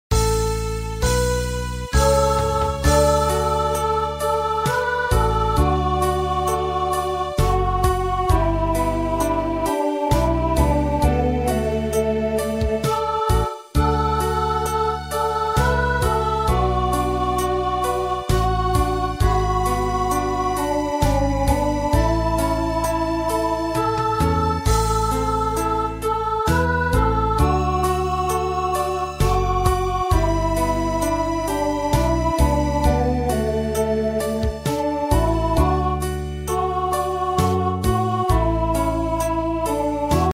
Bird Songs